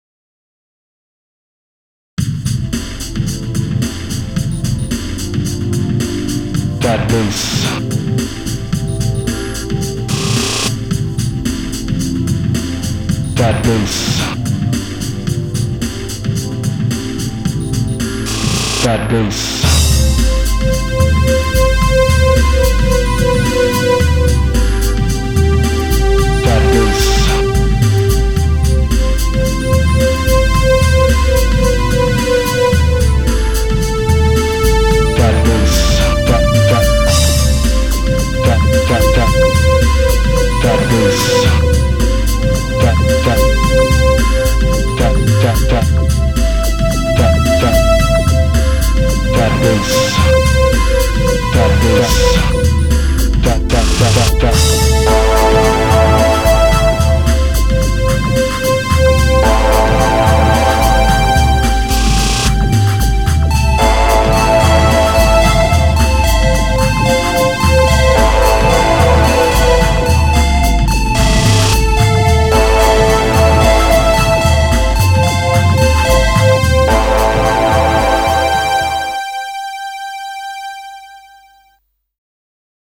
BPM110
Audio QualityMusic Cut